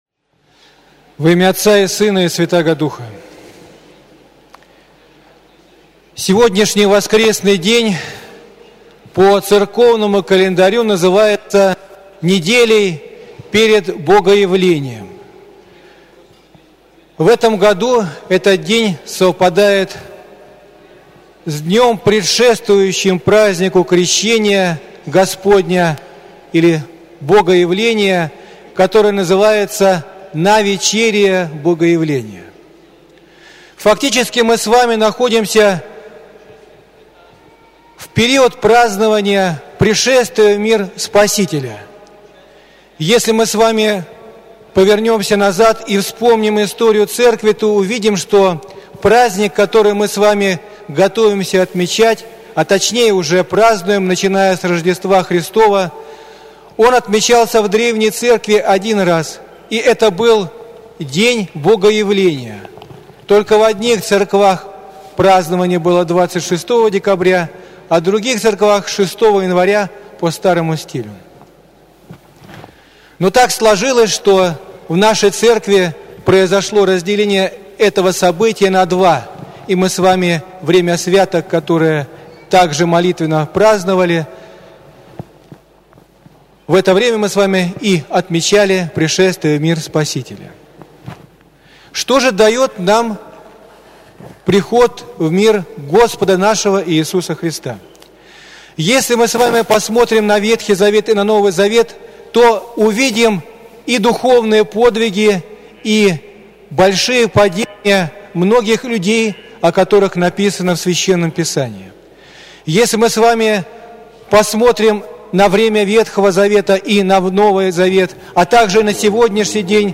Проповедь на Литургии